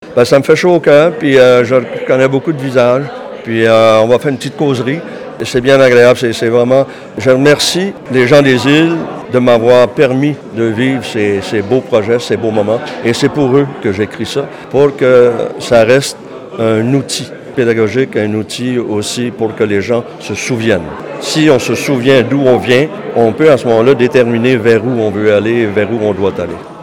Une quarantaine de personnes s’étaient déplacées au Musée de la Mer, dimanche après-midi, pour le lancement du livre.
Maxime Arseneau n’a pas manqué d’exprimer sa gratitude envers ceux et celles qui ont pris part à l’événement.